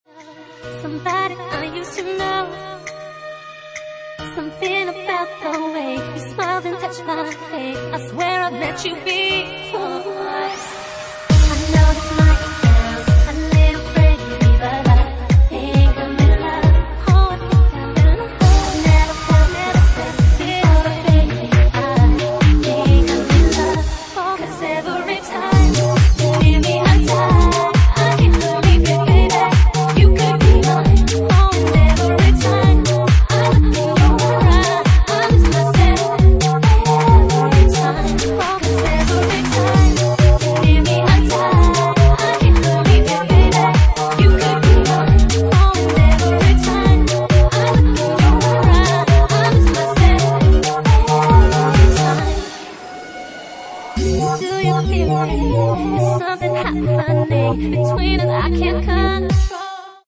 Genre:Bassline House
Bassline House at 135 bpm